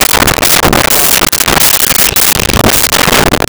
Vehicle Door Old Open Close 02
Vehicle Door Old Open Close 02.wav